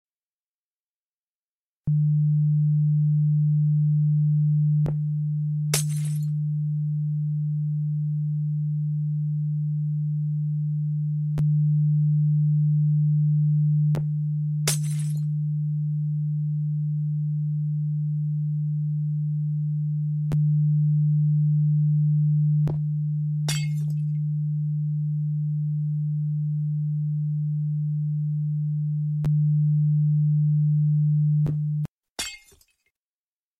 When a long sound is played, its volume randomly increases until another sound is heard. Volume change is preceded by a distinct crackle.
Audio extracted from the video - video_audio.mp3